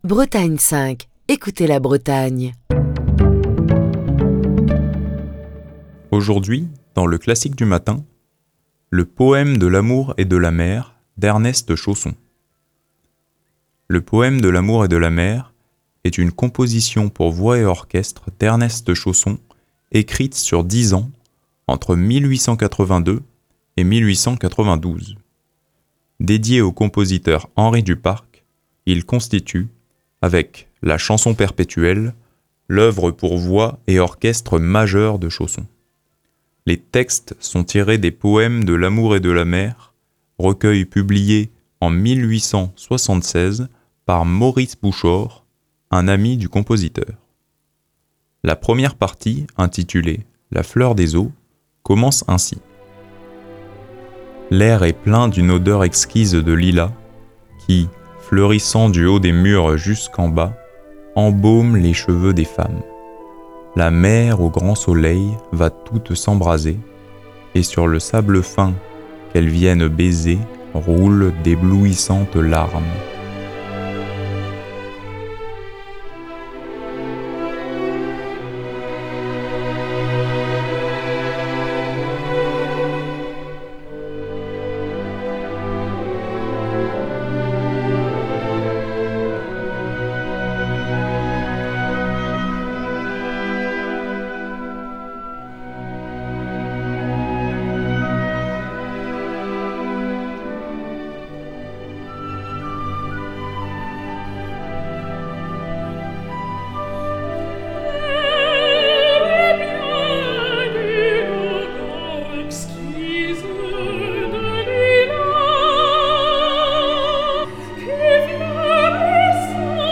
Le Poème de l'amour et de la mer est une composition pour voix et orchestre d'Ernest Chausson, écrite sur 10 ans, entre 1882 et 1892.